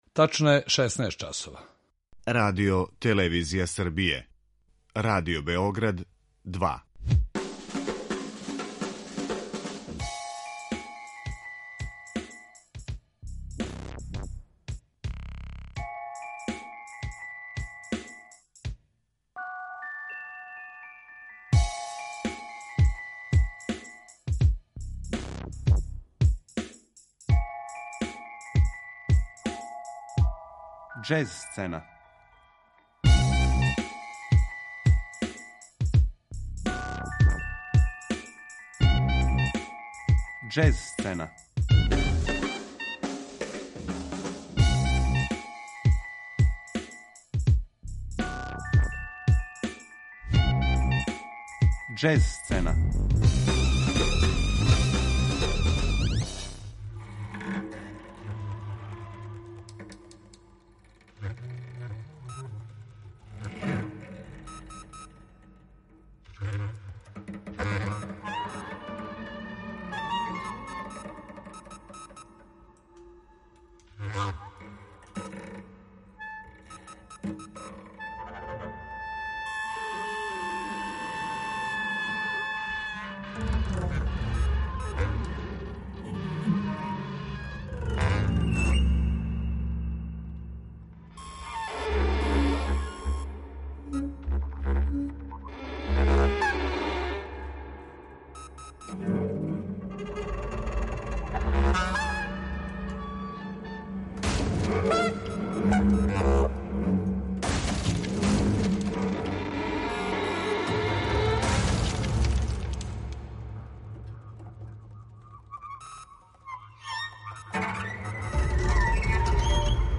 Суботичка мултиинструменталисткиња и композиторка
фри-џез трија